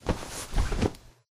rustle4.ogg